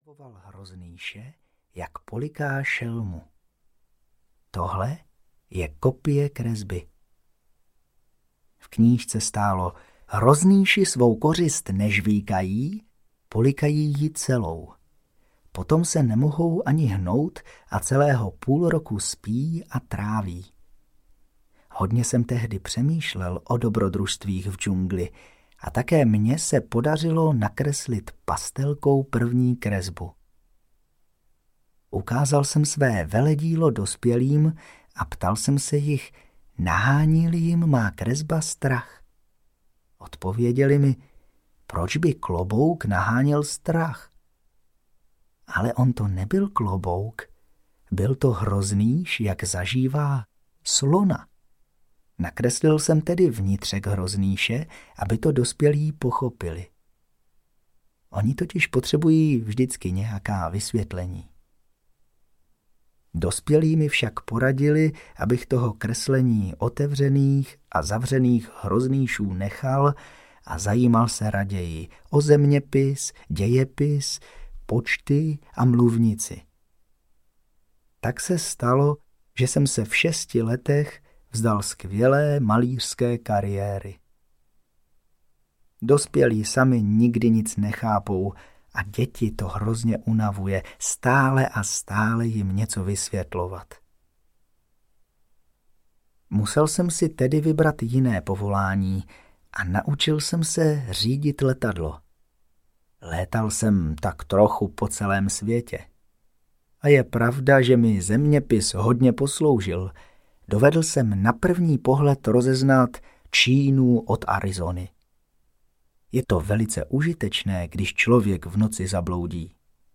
Malý princ audiokniha
Ukázka z knihy